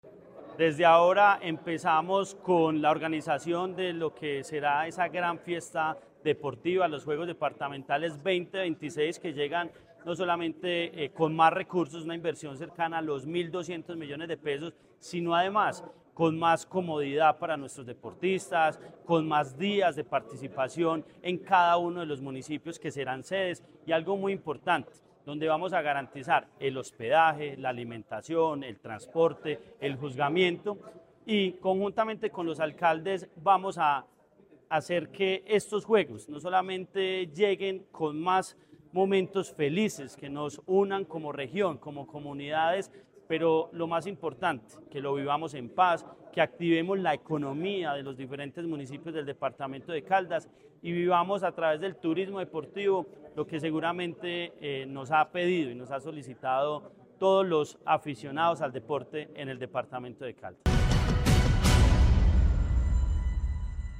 Andrés Duque Osorio, secretario de Deporte de Caldas.
Andres-Duque-Osorio-Sec-deporte-de-Caldas-SORTEO-JUEGOS-DEPARTAMENTALES.mp3